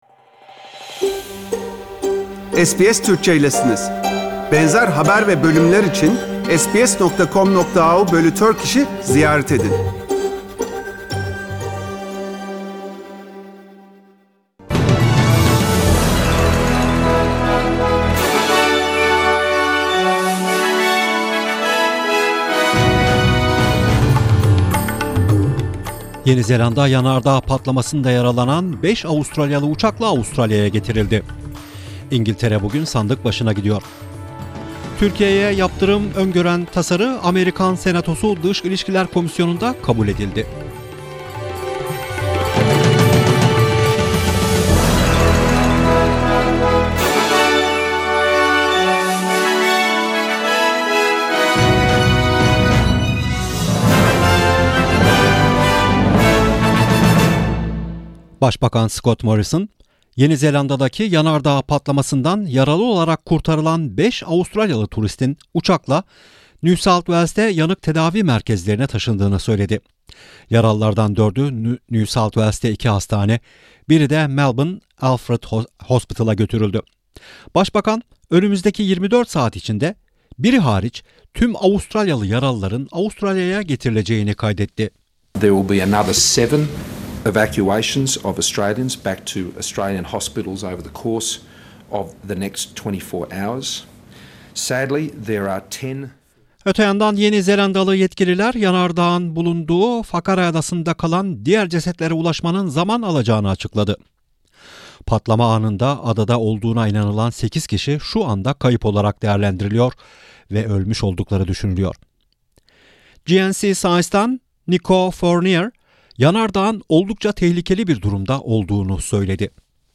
SBS Turkish News